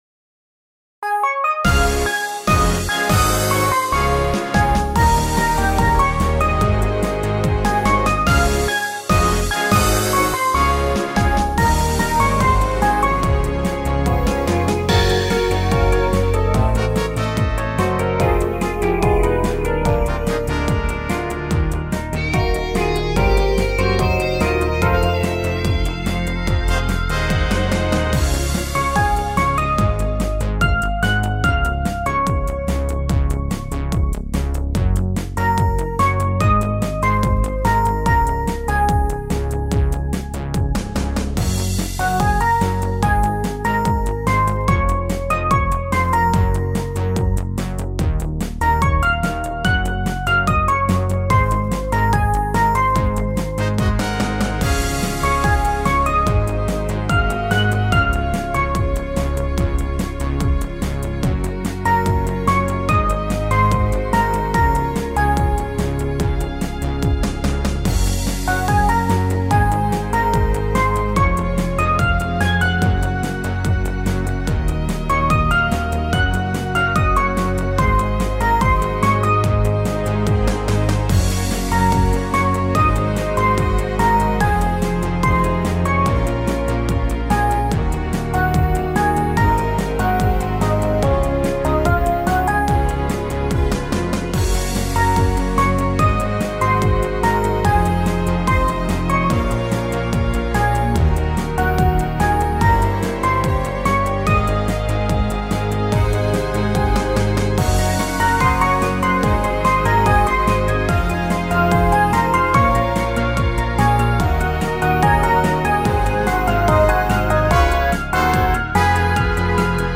アップテンポインストゥルメンタルロング